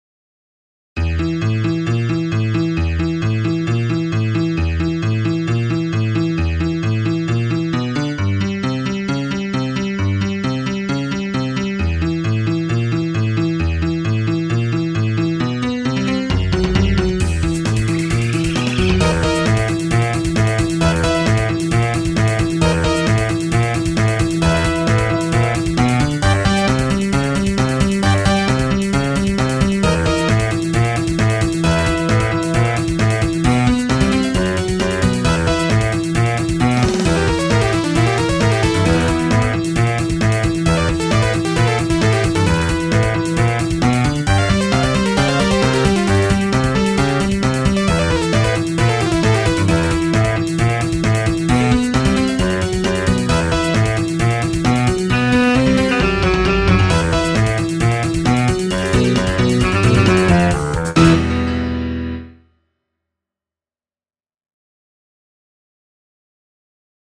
IMO, this is a better version of my last offering, which is basically a ten-bar blues.(Where's the other composers?)